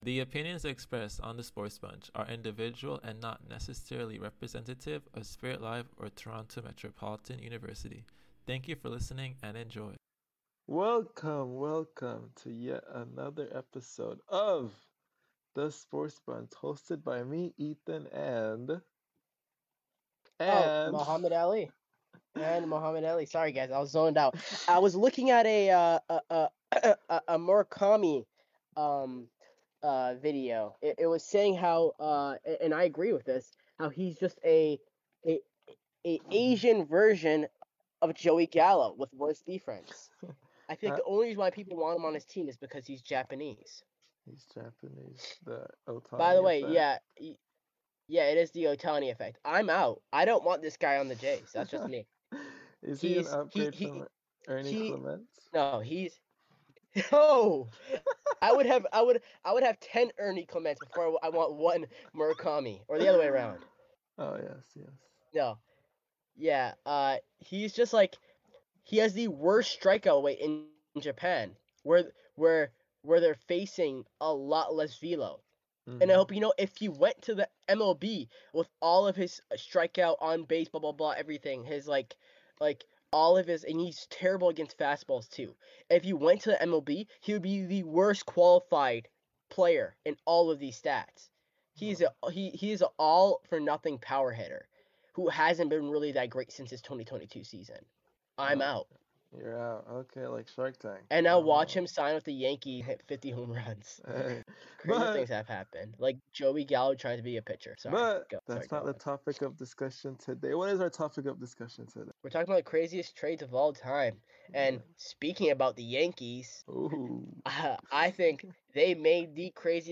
Two friends. One mic.
The SportsBunch is where sports talk gets loud, unfiltered, and fun.